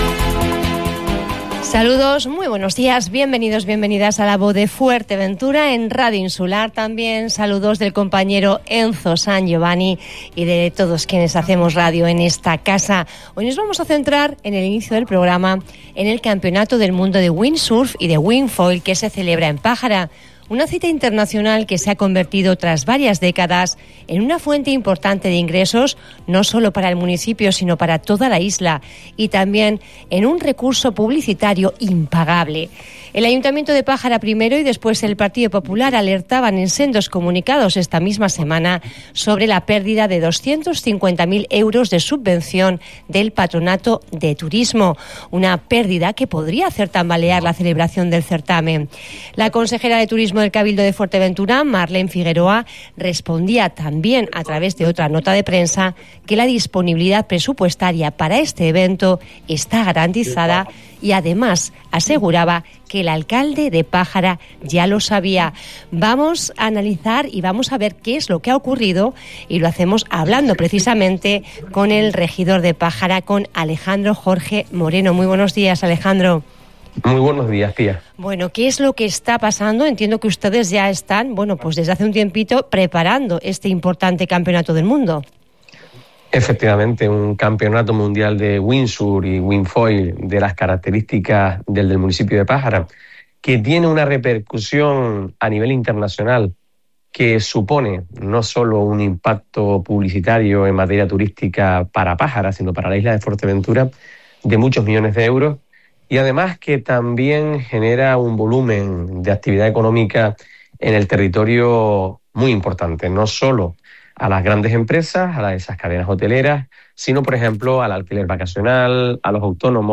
Este viernes, el alcalde de Pájara, Alejandro Jorge, explicó en una entrevista en el programa La Voz de Fuerteventura , en Radio Insular, que la advertencia realizada por el consistorio no pretendía abrir un conflicto institucional, sino asegurar la viabilidad de un evento que considera estratégico para la isla.